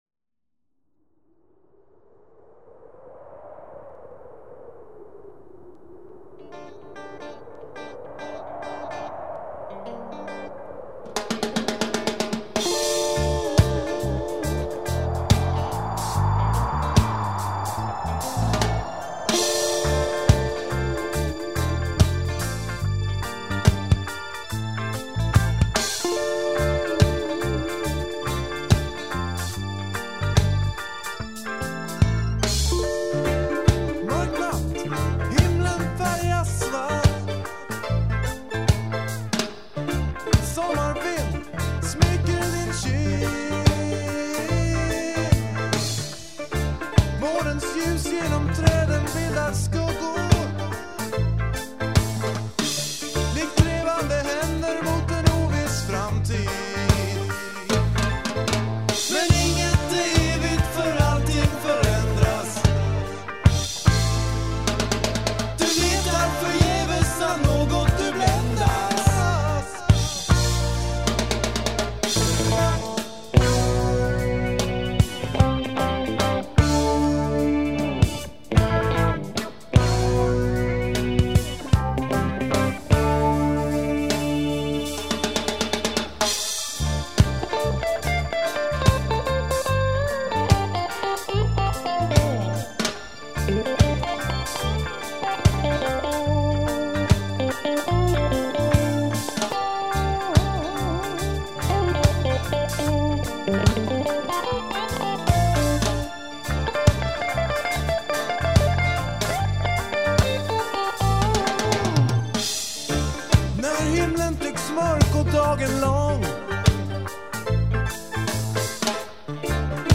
Guitar
Drums
Bass
Trumpet
Trombone
Saxophone